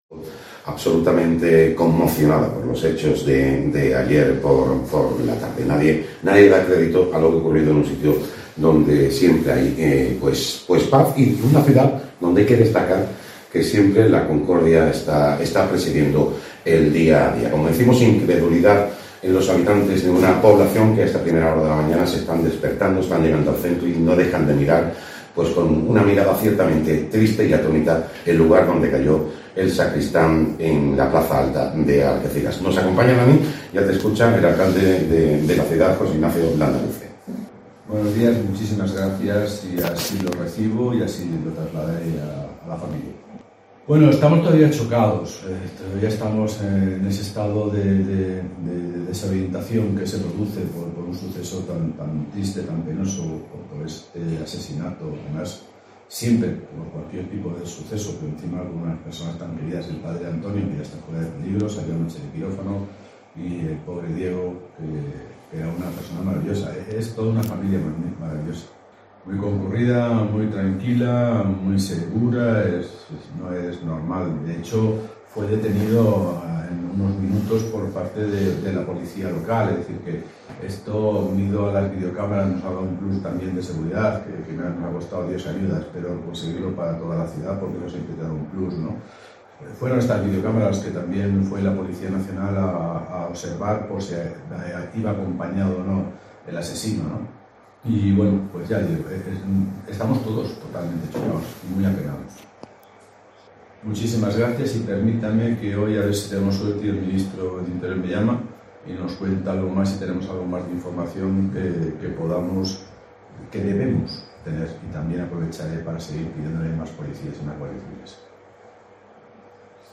El Alcalde de Algeciras, José Ignacio Landaluce, habla en COPE
entrevistado